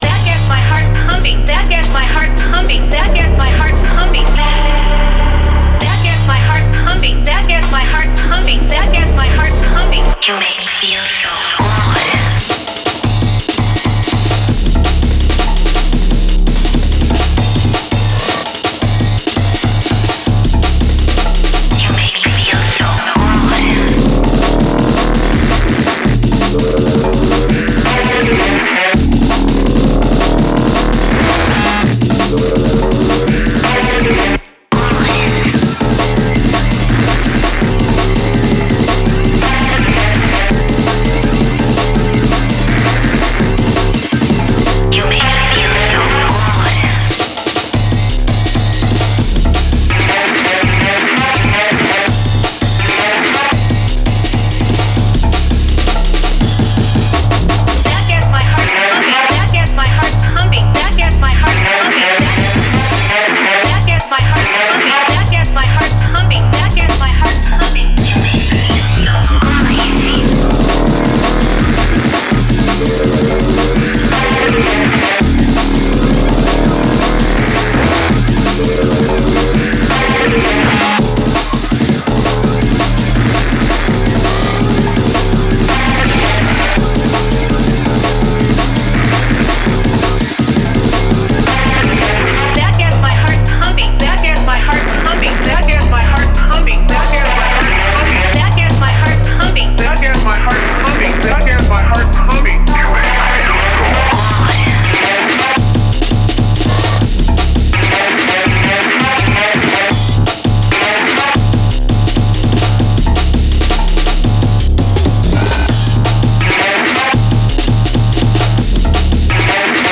a gritty, industrial drum & bass album.